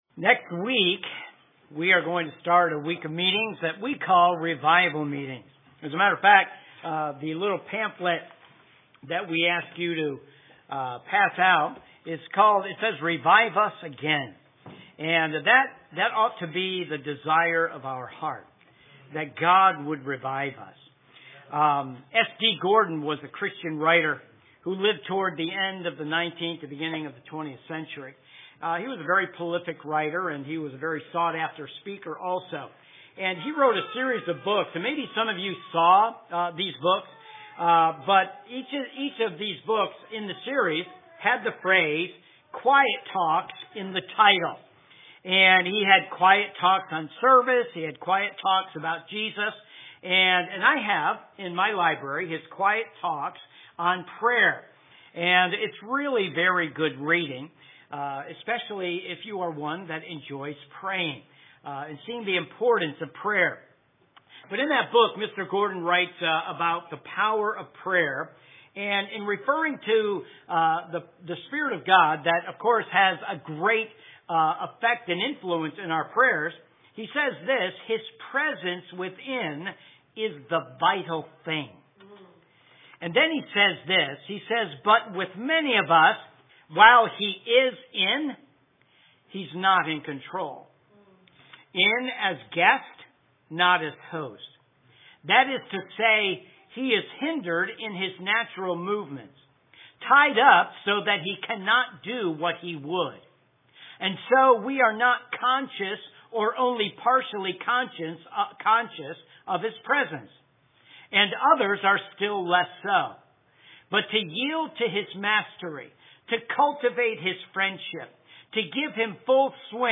AM Messages